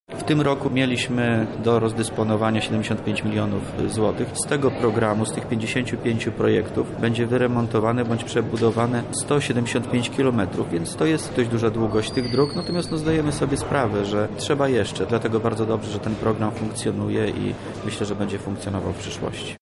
Dziś podpisaliśmy umowy na dofinansowanie modernizację 20 dróg powiatowych i 35 dróg gminnych – mówi Wojewoda Lubelski, Wojciech Wilk